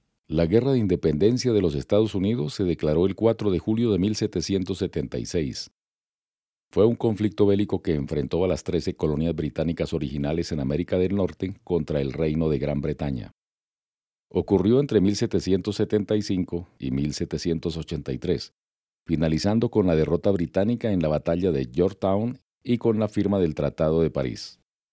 locutor profesional, documentales, actor de voz, comerciales e institucionales
Sprechprobe: eLearning (Muttersprache):